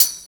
12 TAMB   -R.wav